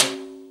prcTTE44027tom.wav